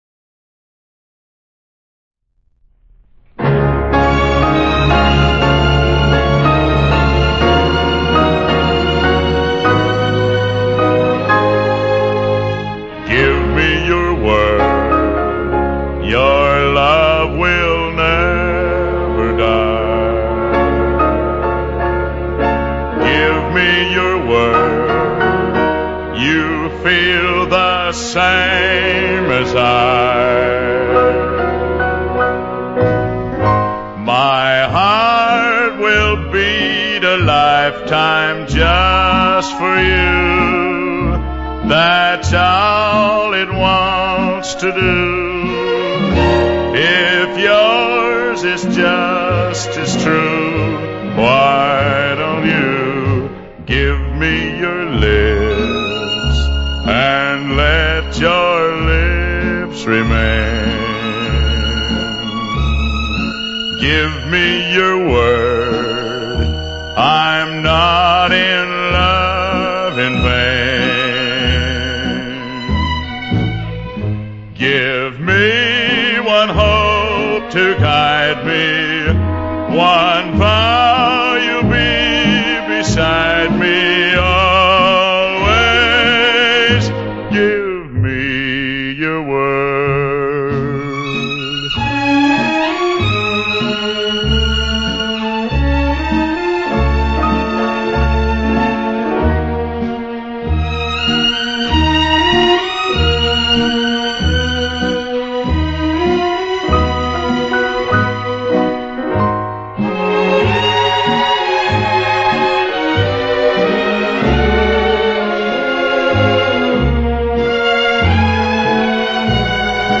американского певца
бас-баритон